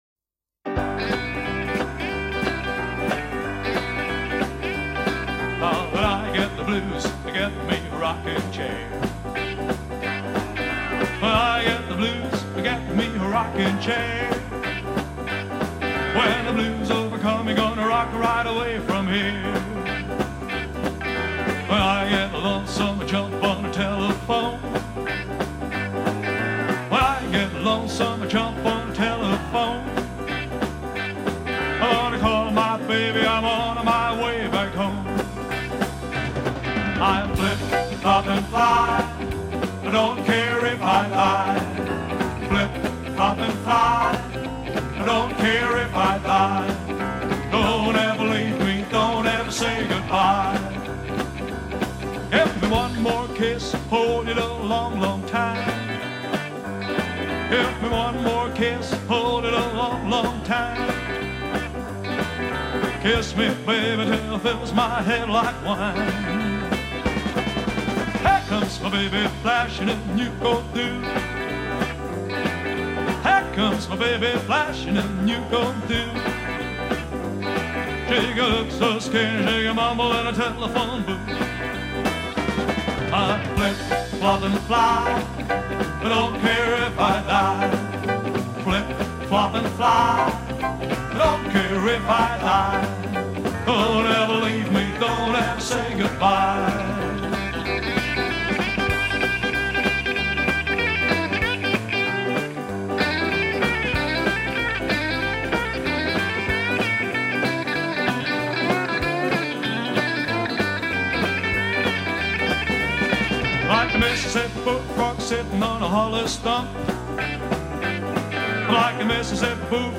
Rock & Roller der Ersten Garde aus München